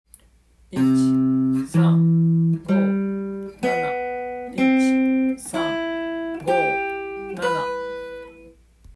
度数練習
②の４和音（３和音の進化したコード）も口ずさんで練習